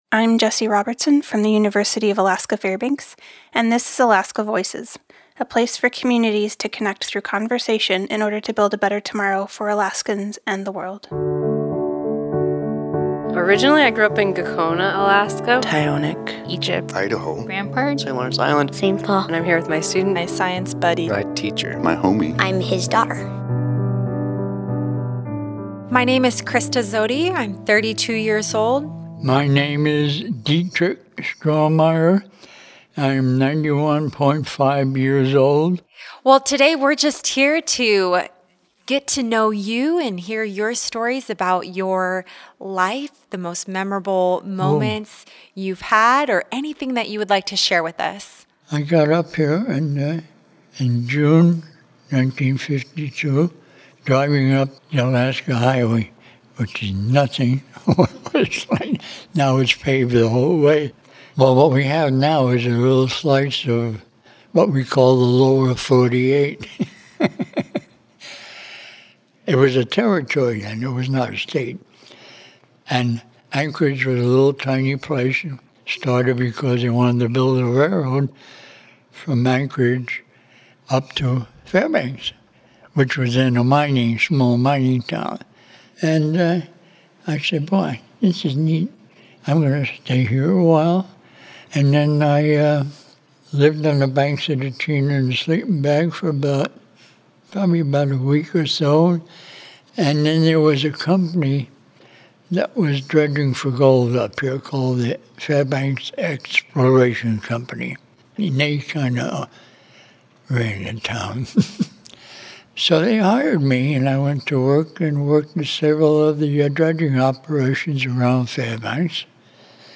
This interview was recorded in collaboration with StoryCorps.